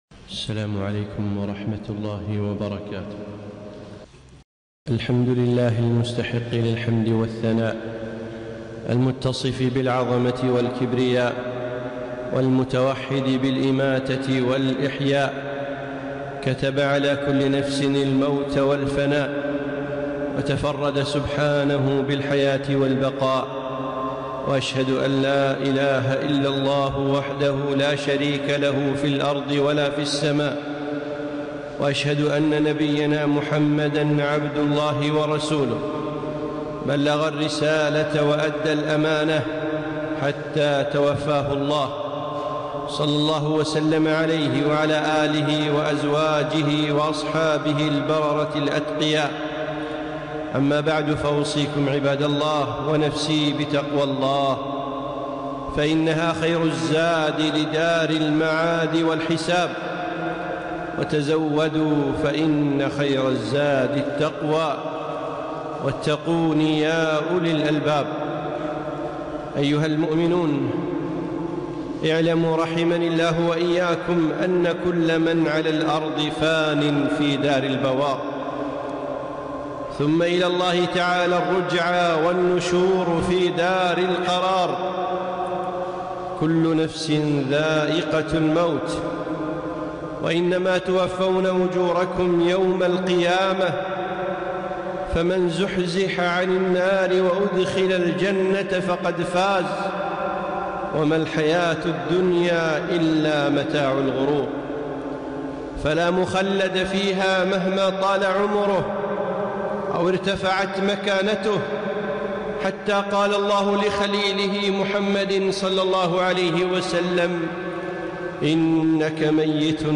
خطبة - قائد العمل الإنساني